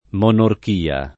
monorchia [ m q nork & a ]